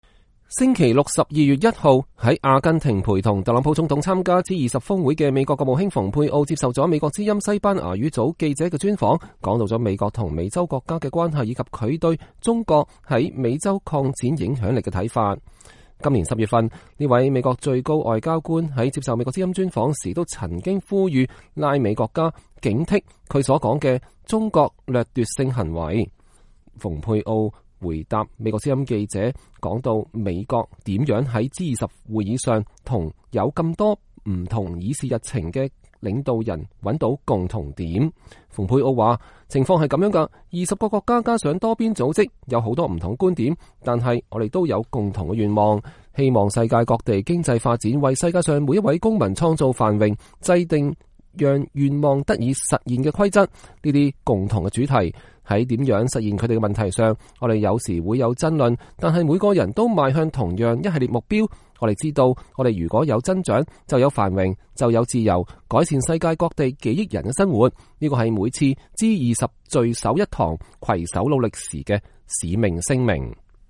美國國務卿接受VOA專訪對中國進入美洲方式表示關注